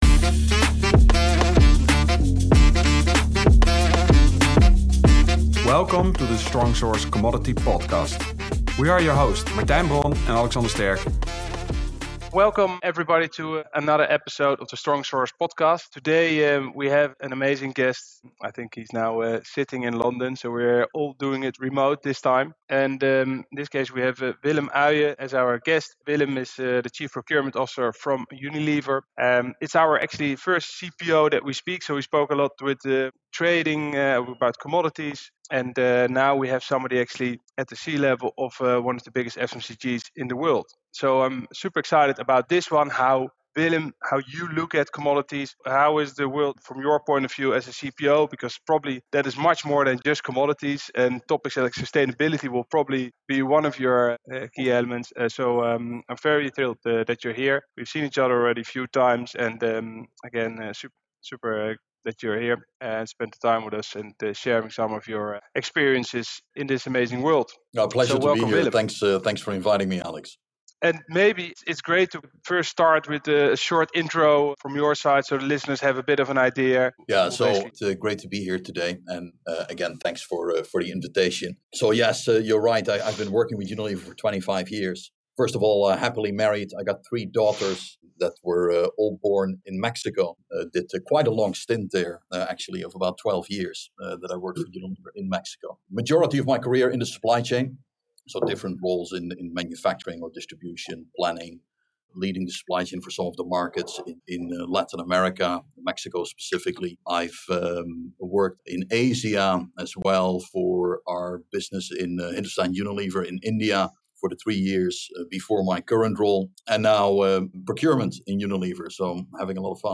Tune in for an engaging conversation with one of the top leaders in the FMCG industry.